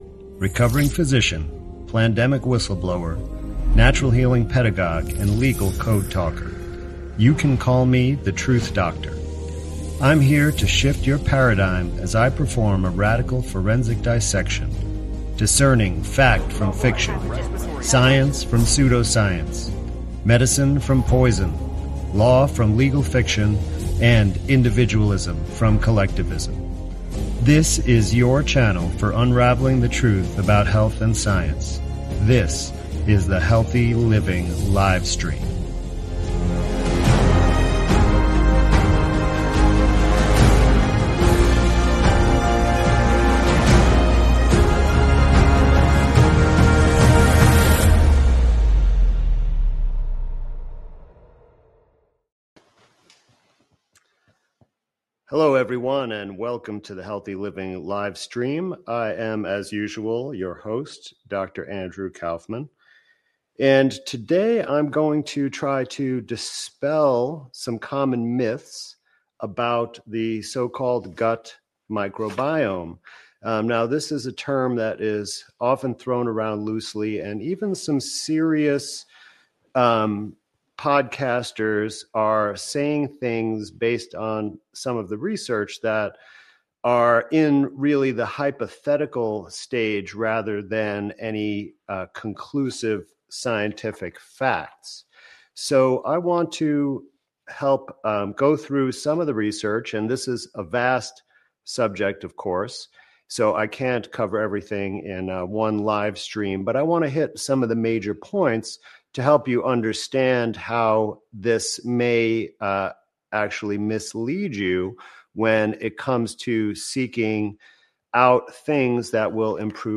Healthy Living Livestream: Youve Been Lied to About Your Gut Flora